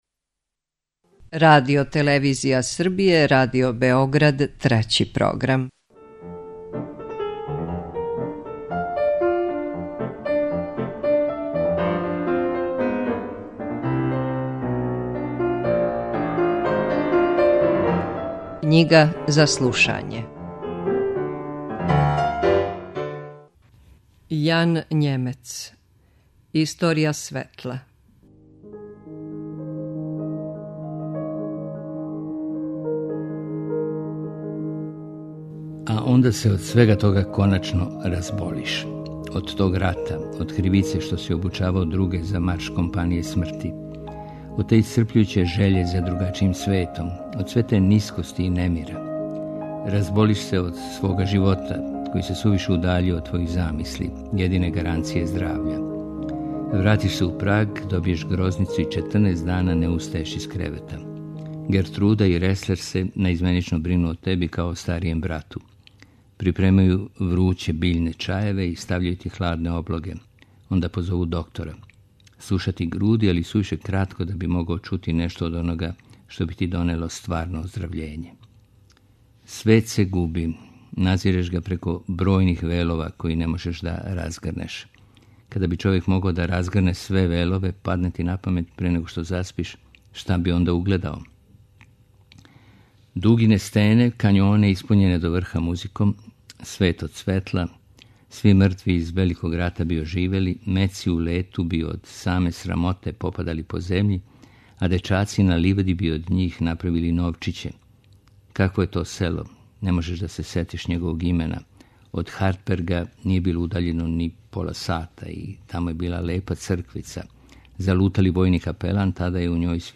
преузми : 9.20 MB Књига за слушање Autor: Трећи програм Циклус „Књига за слушање” на програму је сваког дана, од 23.45 сати.